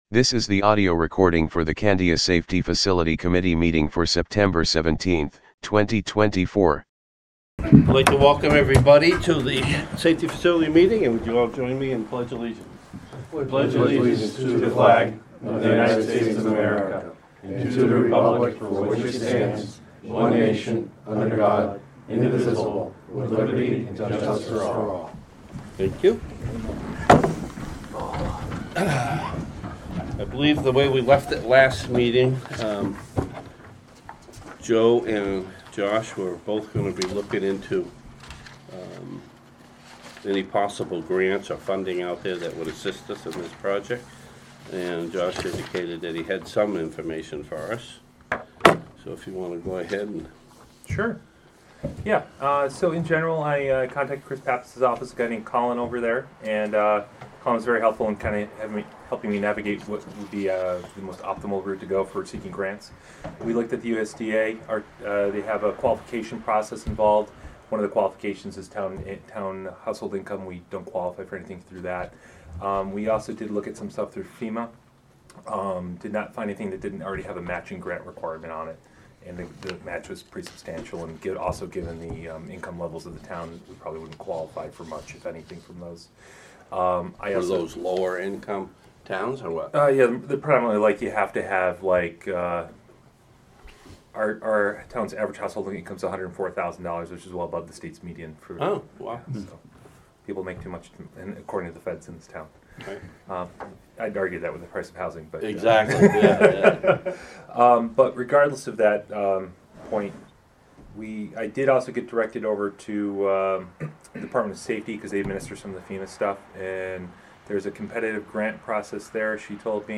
Audio recordings of committee and board meetings.
Safety Facility Committee Meeting